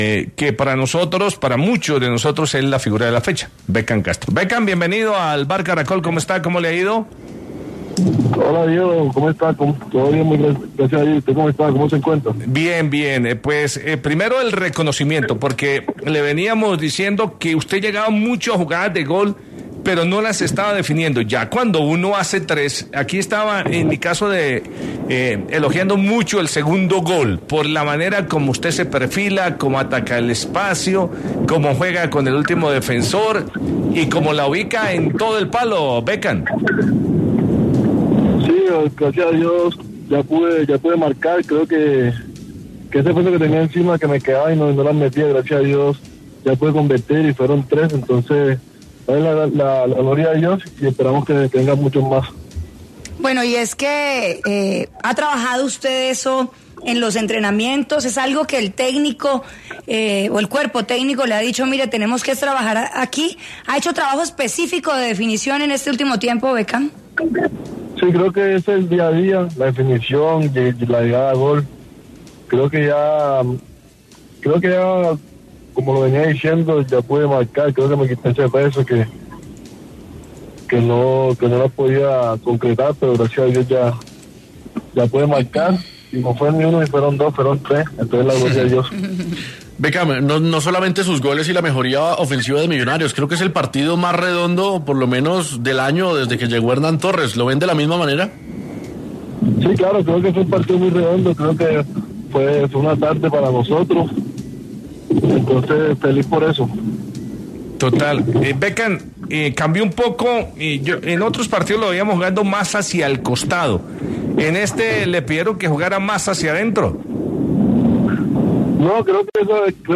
Es uno de los animadores de la tabla de máximos artilleros del semestre y, en diálogo con El VBar Caracol, prometió que llegará a 12.